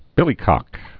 (bĭlē-kŏk)